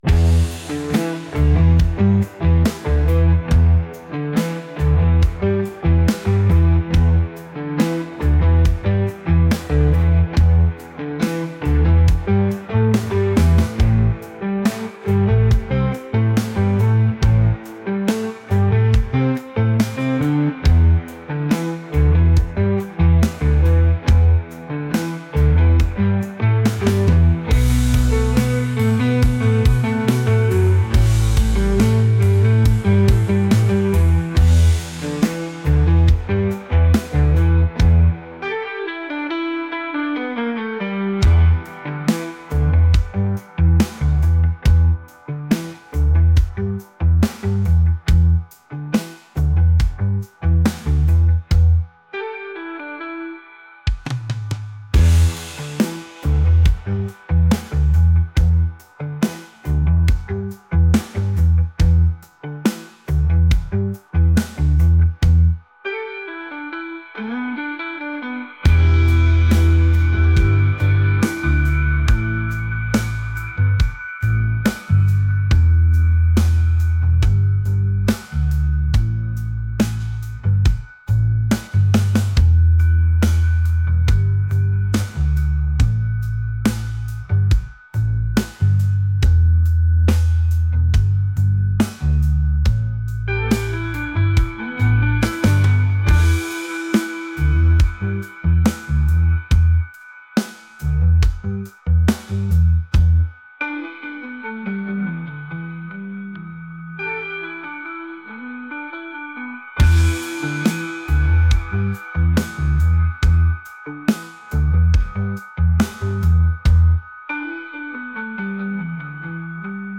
rock | groovy